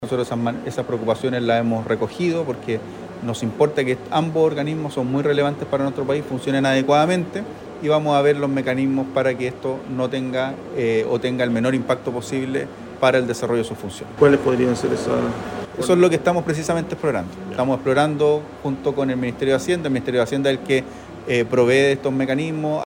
Las declaraciones las entregó en su visita a Coronel, donde landó el nuevo Servicio de Reinserción Social Juvenil, que reemplazará al Sename.